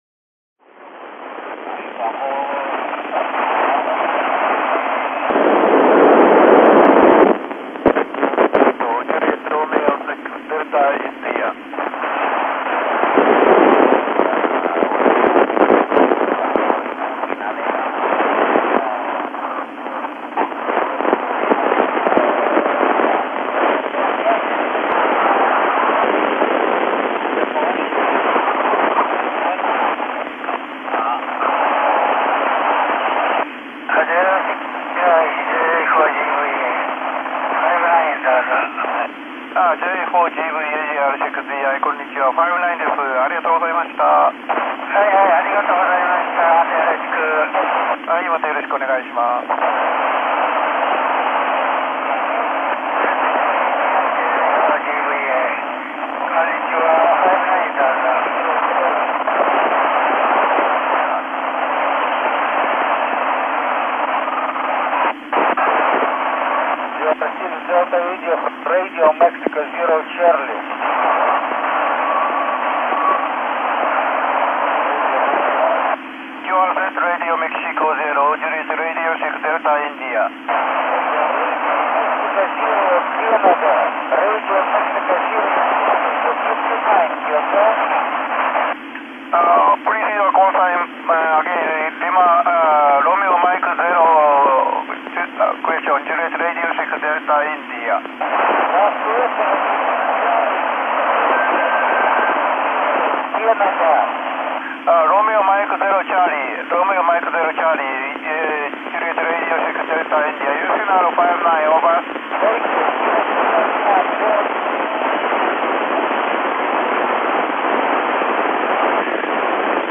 というわけで、AO-91 へ。ちょうど西の水平線スレスレの軌道だったので、何にも聞こえないと思ったら、
この録音のようにしっかり聞こえた。西の方の局が交信してましたが、勝手に掲載ご容赦。